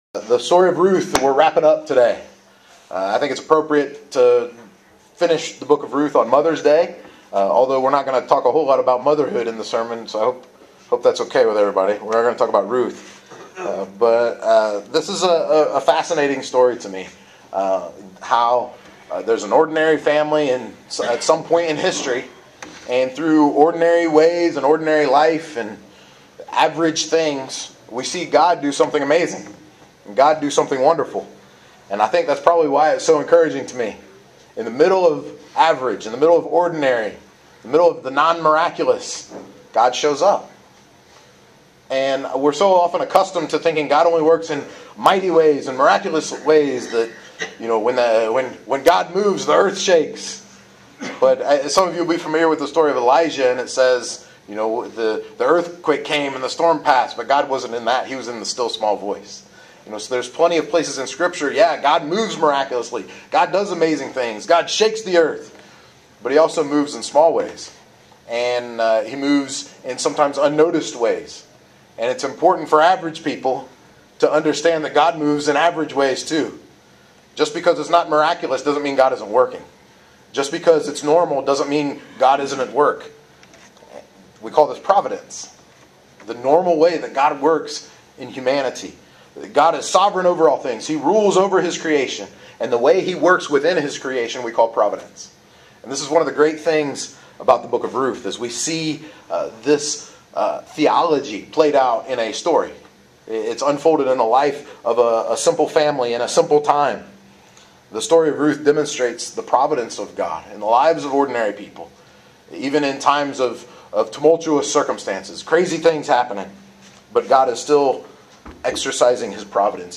Part five of a five part teaching series through the Old Testament Book of Ruth